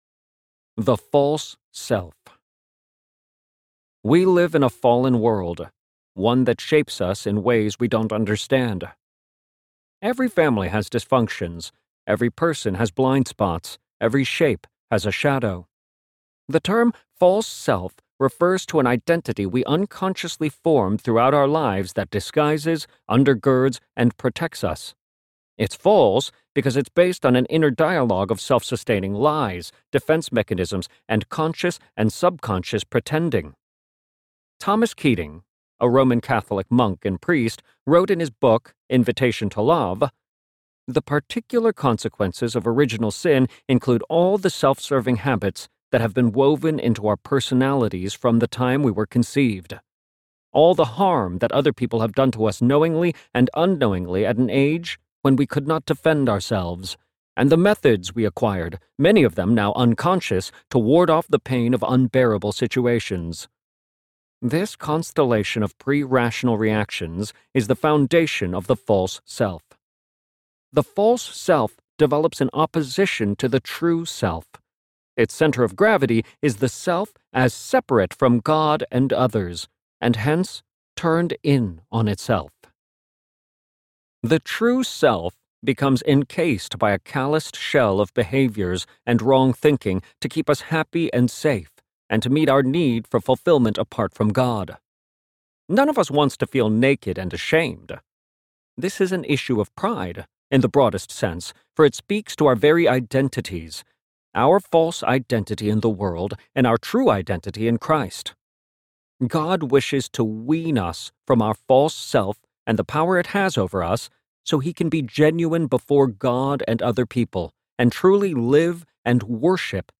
Honest Worship Audiobook
Narrator
5.57 Hrs. – Unabridged